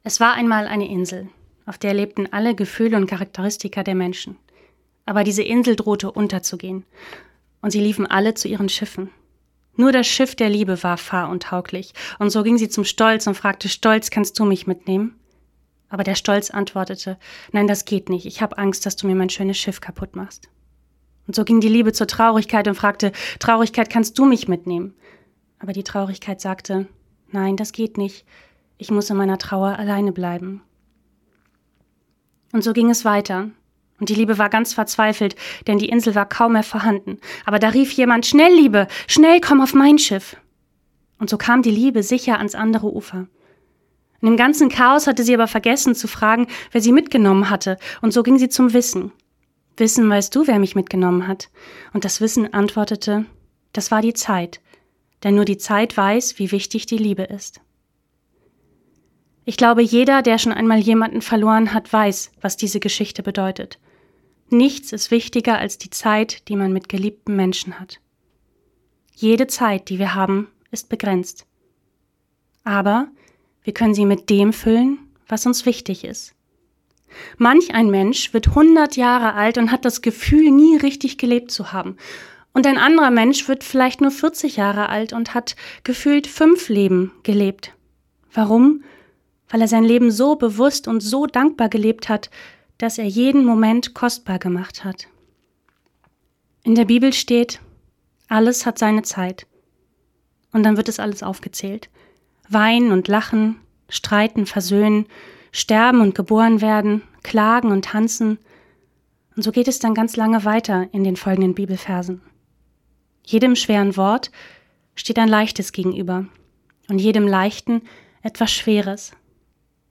Radioandacht vom 20. Juni